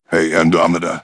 synthetic-wakewords
synthetic-wakewords / hey_andromeda /ovos-tts-plugin-deepponies_Kratos_en.wav
ovos-tts-plugin-deepponies_Kratos_en.wav